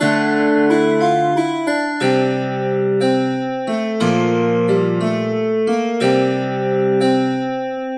弦乐 5 90 Bpm
Tag: 90 bpm Weird Loops Strings Loops 1.35 MB wav Key : Unknown